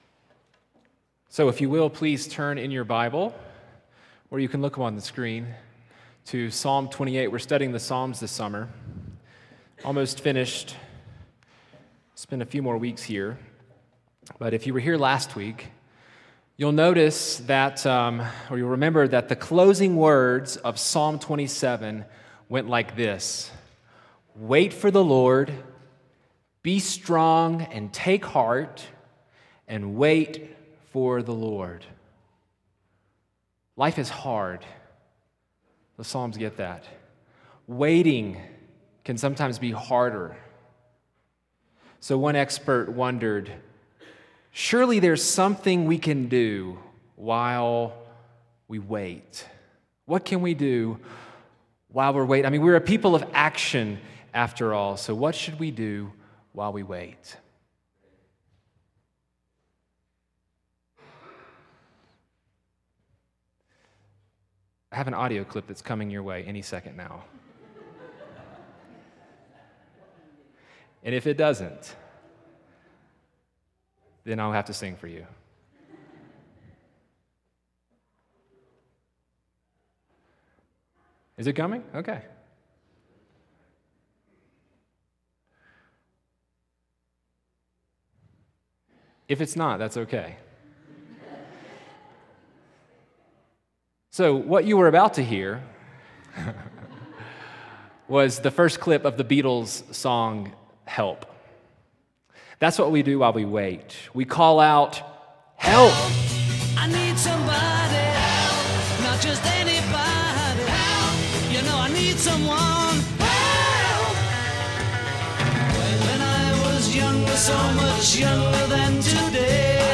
Categories: Sermons